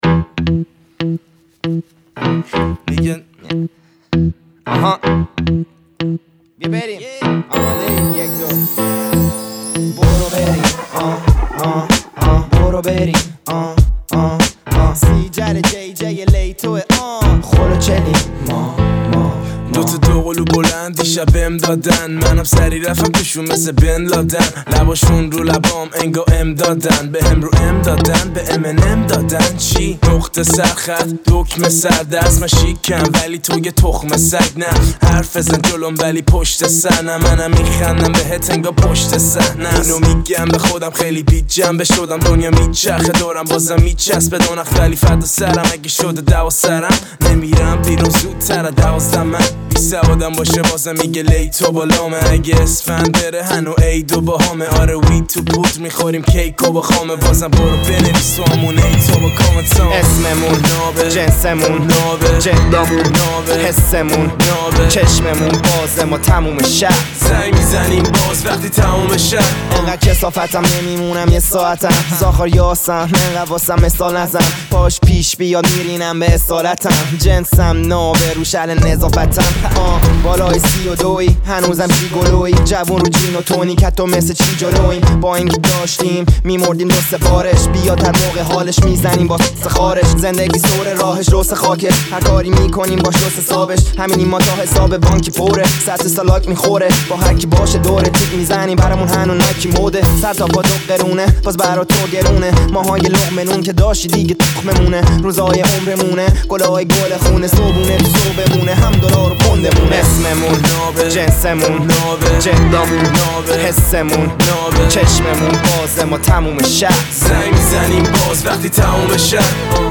رپ